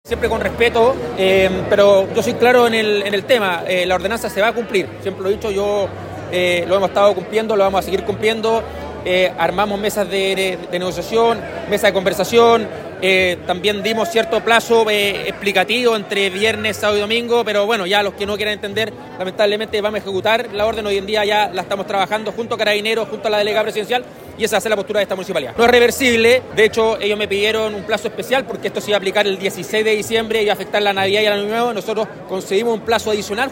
Ante esto, el alcalde Rodrigo Wainraihgt, reafirmó que la ordenanza se cumplirá.
rodrigo-wainraihgt-alcalde-cuna.mp3